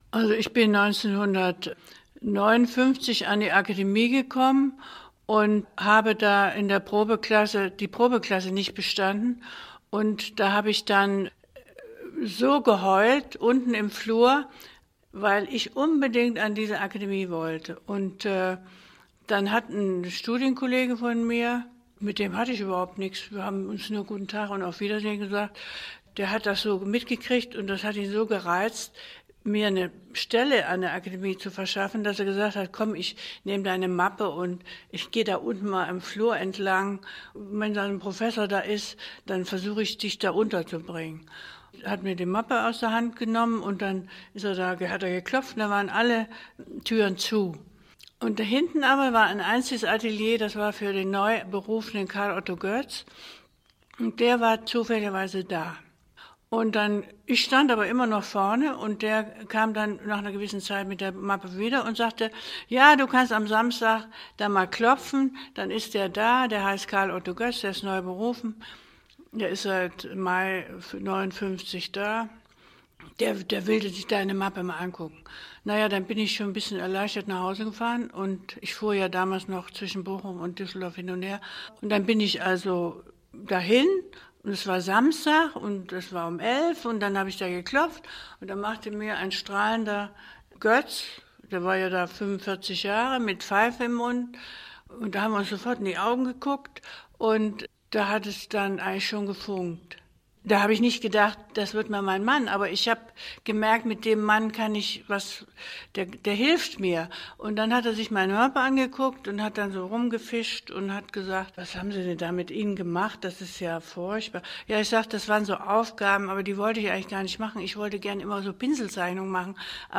Rissa berichtet im Interview über ihre Aufnahme an die Kunstakademie und die Klasse Karl Otto Götz.